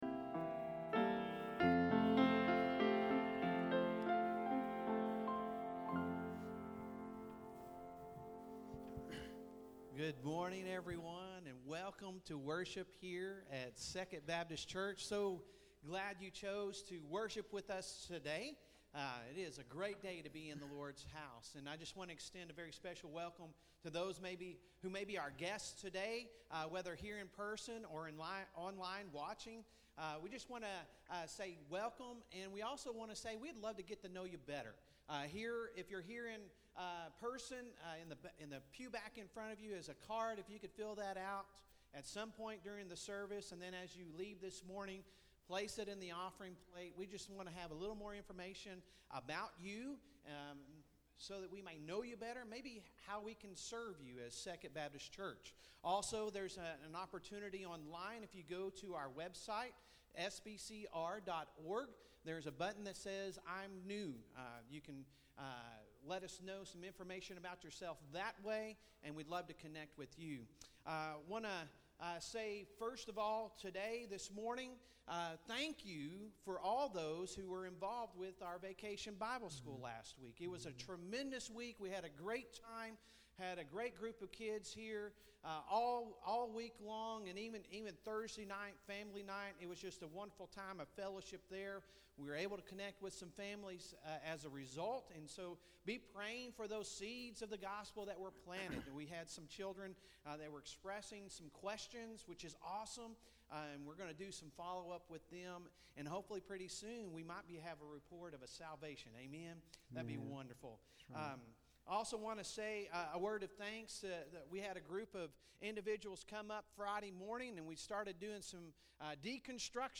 Sunday Sermon June 11, 2023 | Second Baptist Church
Sunday Sermon June 11, 2023